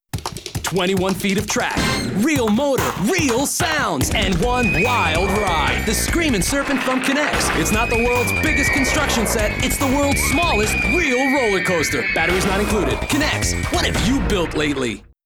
VOICE OVERS Television and Radio
TELEVISION